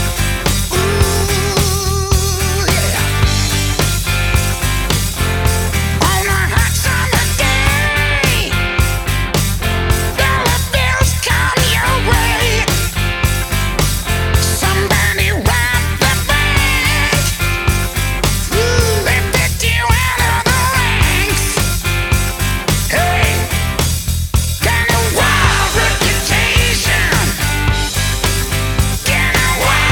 • Hard Rock